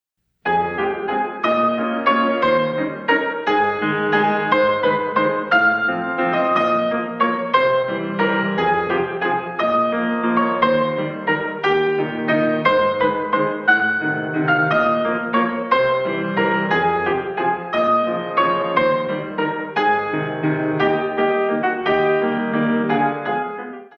128 Counts